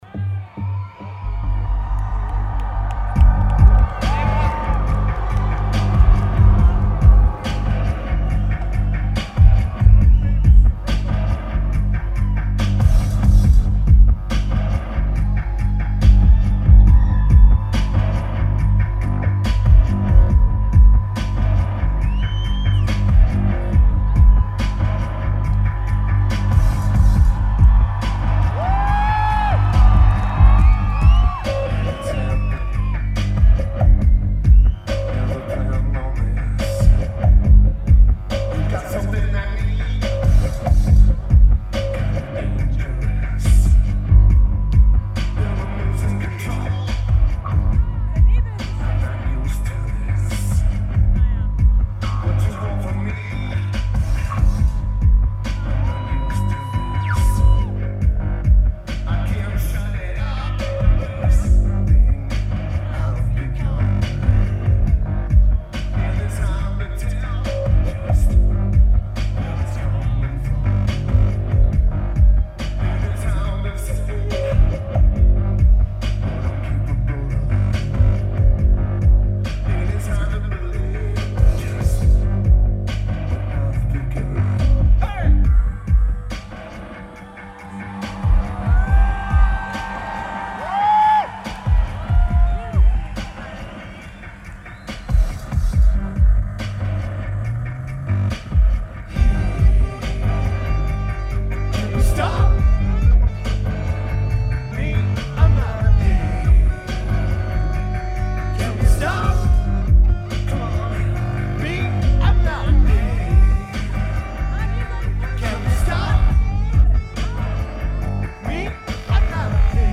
Berlin Germany
Keyboards/Bass/Backing Vocals
Drums
Guitar
Vocals/Guitar/Keyboards
Lineage: Audio - AUD (DPA 4061 + CA-UBB + R09)